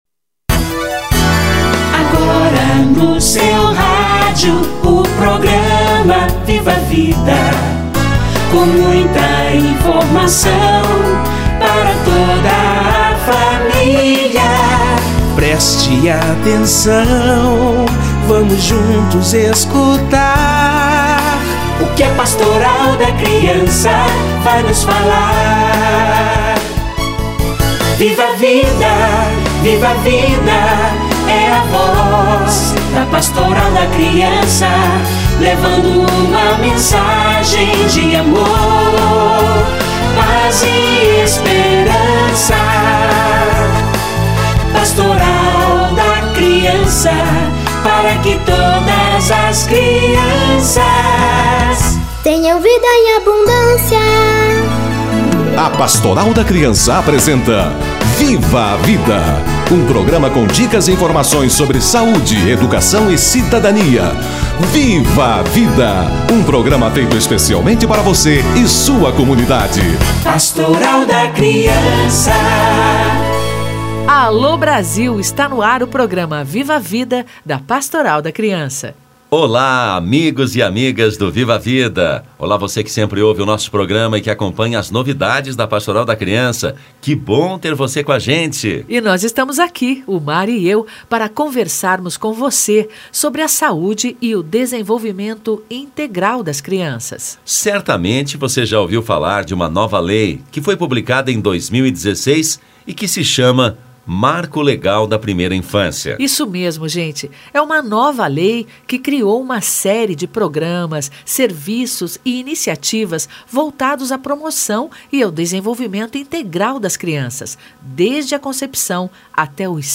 Marco Legal da Primeira Infância - Entrevista